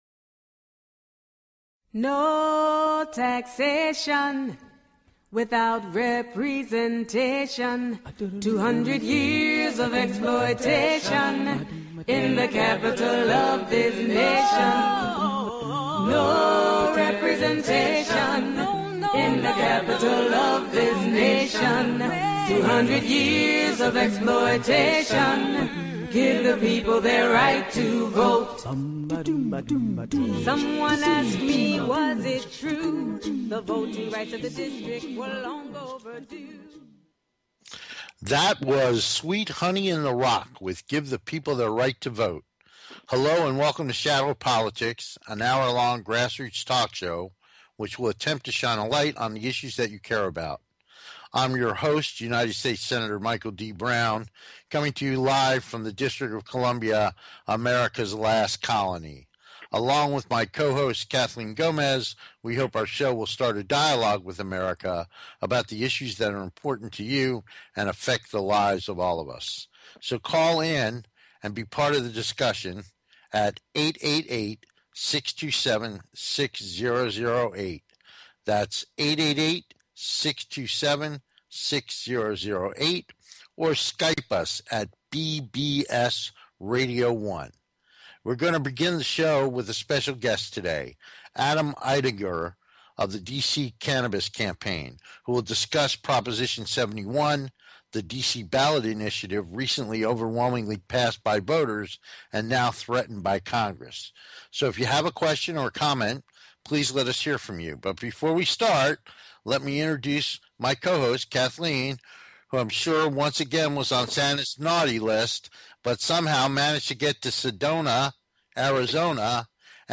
Shadow Politics is a grass roots talk show giving a voice to the voiceless.
We look forward to having you be part of the discussion so call in and join the conversation.